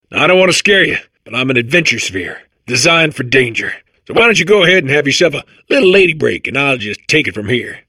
The Adventure Sphere (who calls himself "Rick") is a corrupted personality core of male programming who appears in the single-player campaign of Portal 2.
The Adventure Sphere speaks in the manner of a stereotypical "tough guy", spending most of his time boasting about his bravery and excitement for danger.
Rick, along with the other corrupted cores and the defective turrets, is voiced by Nolan North.